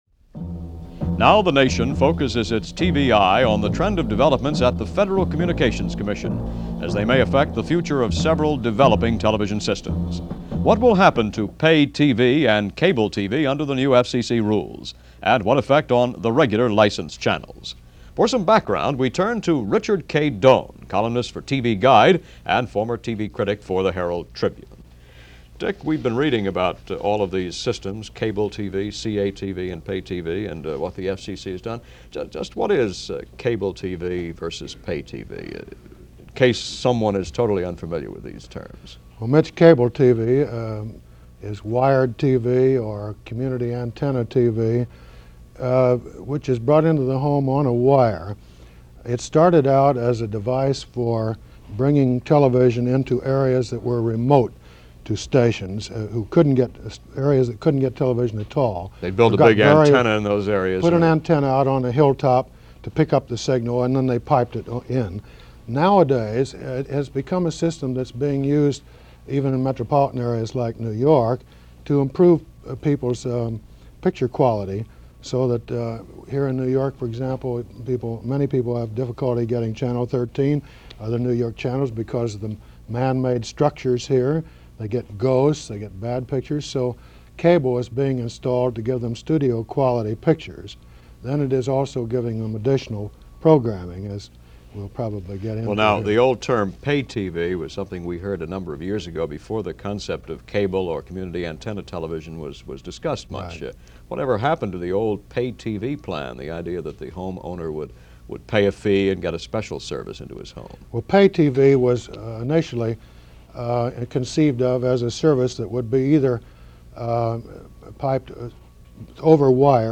Cable TV: Is There A Future? - 1968 -Discussion on the future of Cable TV in American Homes.